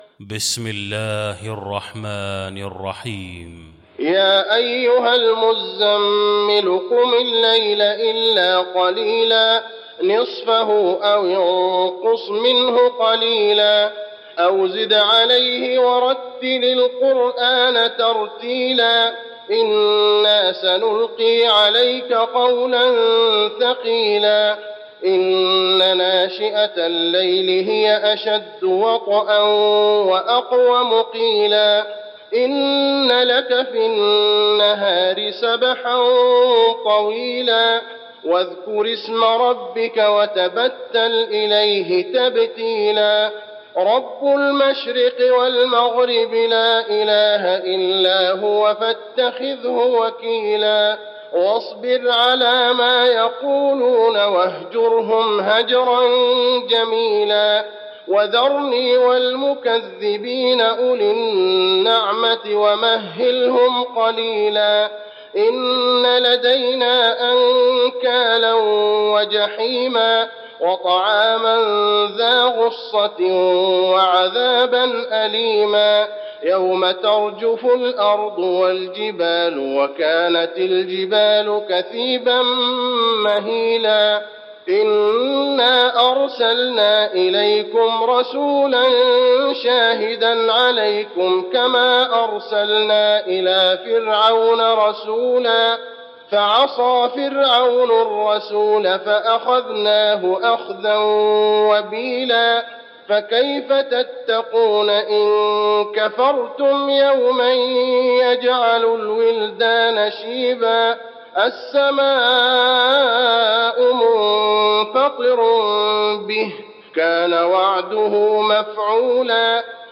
المكان: المسجد النبوي المزمل